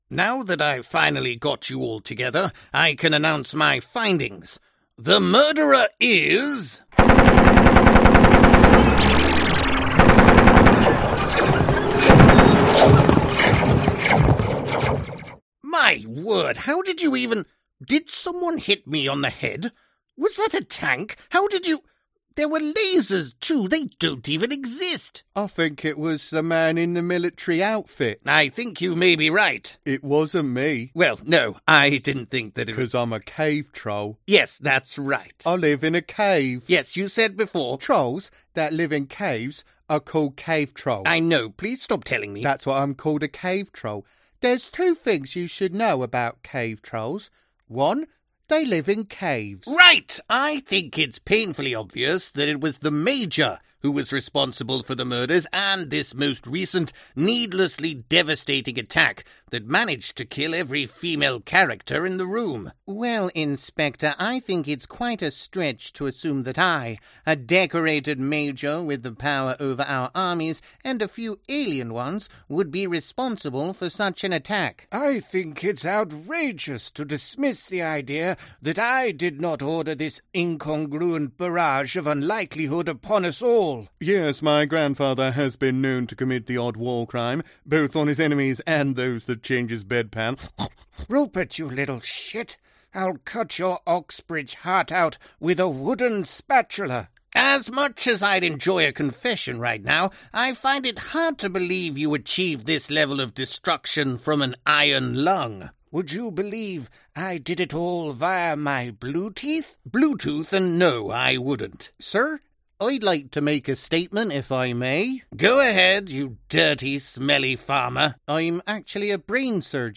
Hallo, ich bin ein britischer männlicher Sprecher mit einer warmen und vertrauenswürdigen Stimme, die sich für Unternehmenspräsentationen und E-Learning eignet, aber auch die Bandbreite hat, um an skurrilen Werbespots, Animationen und Videospielen zu arbeiten.
Sprechprobe: Sonstiges (Muttersprache):
Hi, I'm a male British voiceover with a warm and trustworthy voice suitable for corporate narration and elearning but with the range to also work on quirky commercials, animation, and video games. I've been a voiceover for ten years now working with clients internationally from my home studio in Mexico City.